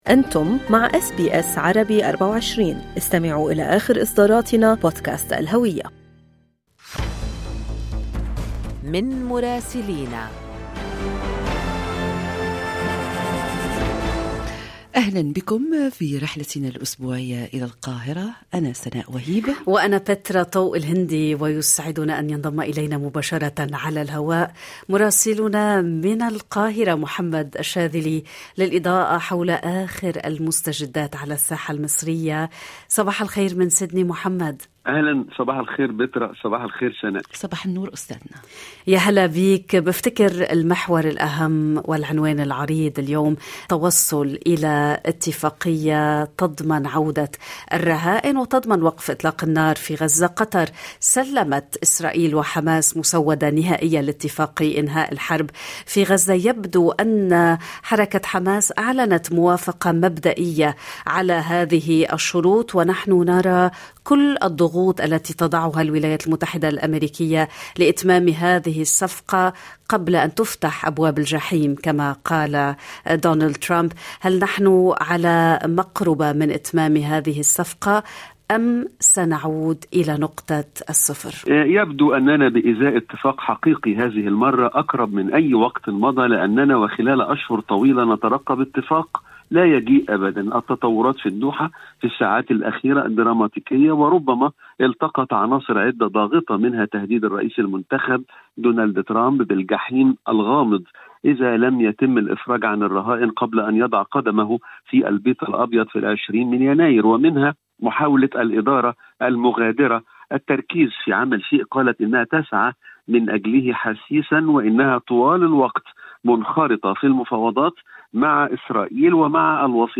يمكنكم الاستماع إلى تقرير مراسلنا في مصر بالضغط على التسجيل الصوتي أعلاه.